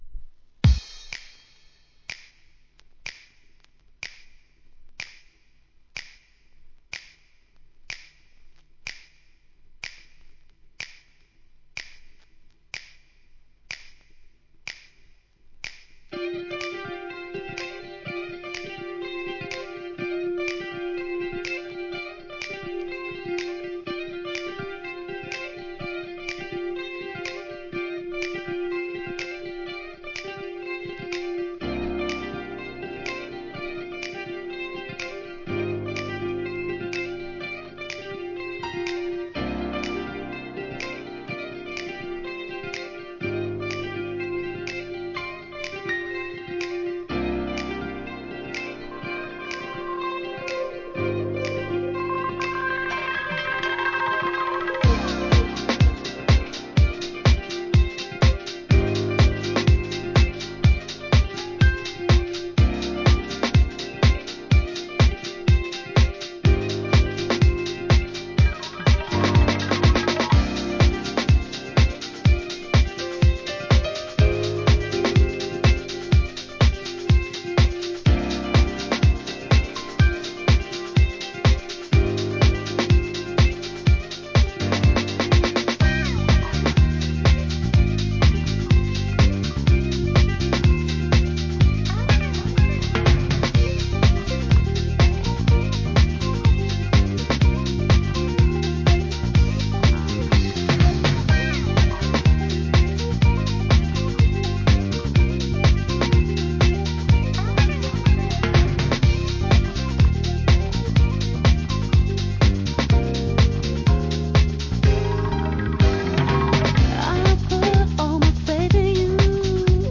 ラテン〜ジャジーな良質作！！